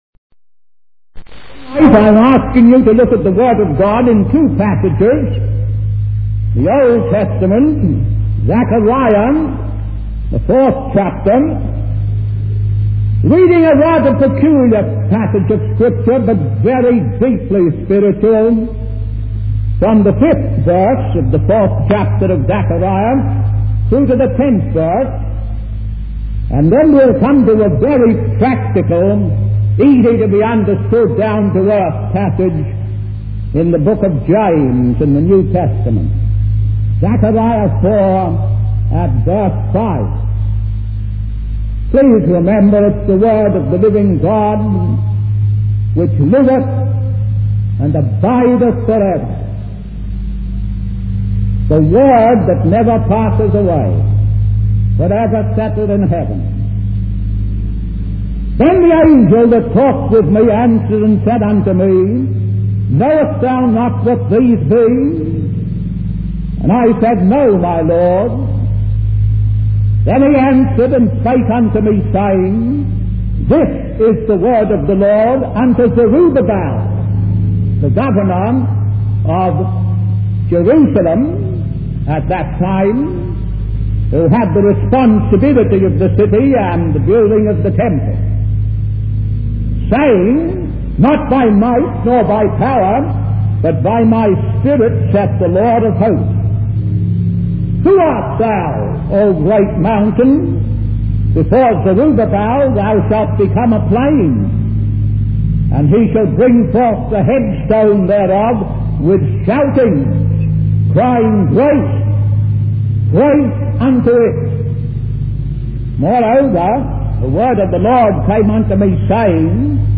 In this sermon, the preacher emphasizes the importance of small things in the eyes of God. He refers to a biblical passage where the Prophet cries out about studying the day of small things. The preacher also mentions James, the practical apostle, who highlights the power of a little fire that can kindle a great matter.